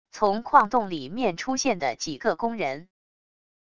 从矿洞里面出现的几个工人wav音频